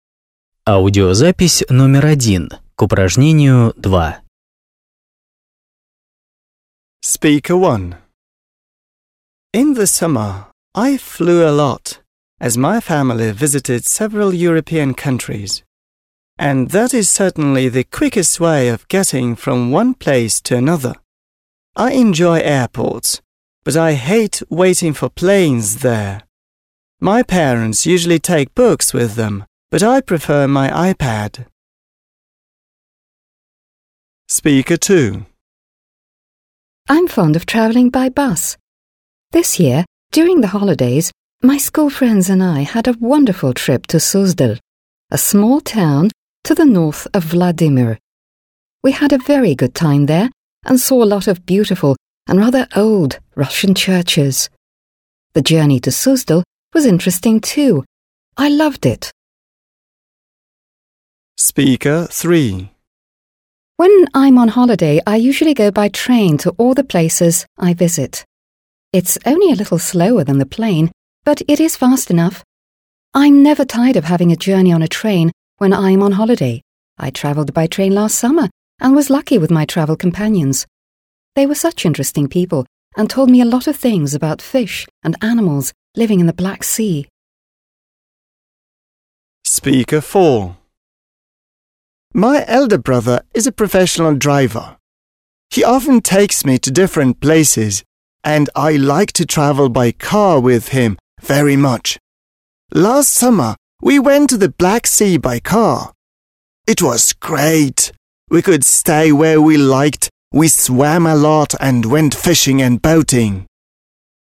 2. Послушайте, как четыре человека говорят о своем отпуске.